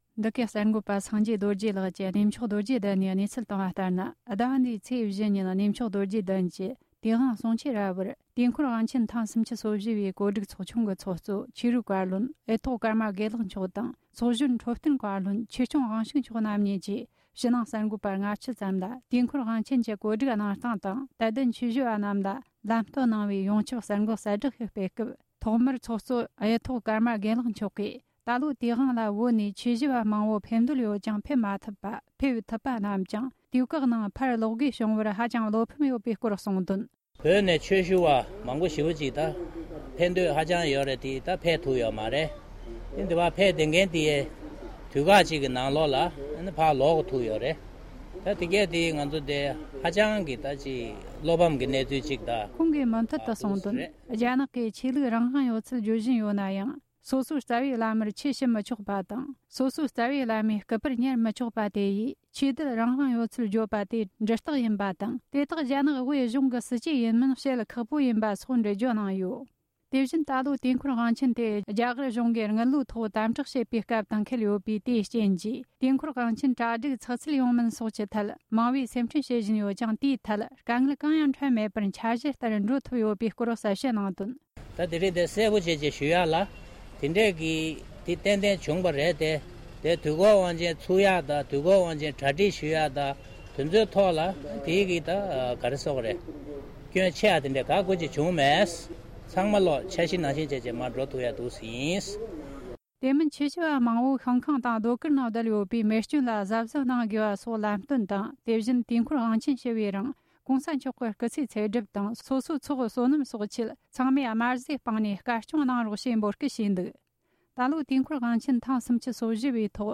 སྒྲ་ལྡན་གསར་འགྱུར། སྒྲ་ཕབ་ལེན།
དུས་དབང་གོ་སྒྲིག་ཚོགས་ཆུང་གི་ངོས་ནས་གསར་འགོད་གསལ་བསྒྲགས་གནང་སྐབས། ༢༠༡༧།༡།༤